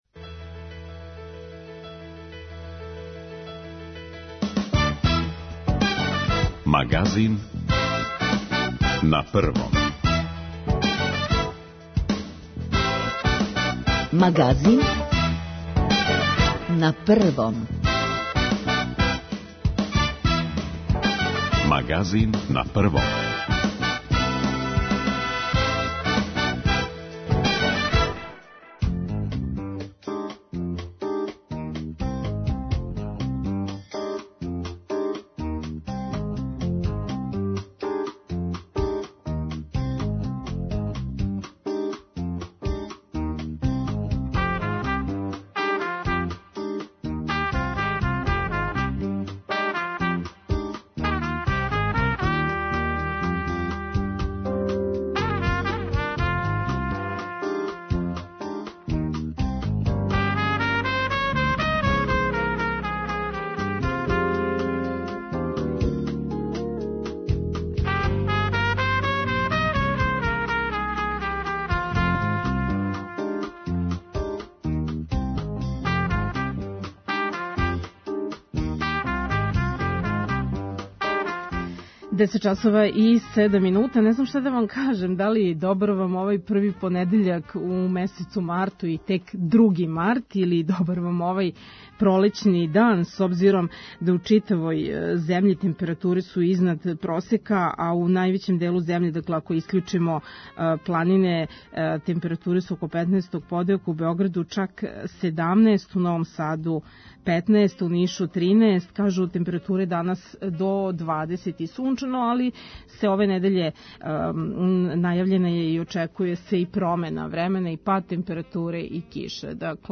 Рубрика „Спорт - више од игре" доноси нове спортске информације и занимљивости. У другом сату емисије пружамо вам прилику да се укљућите у наш програм.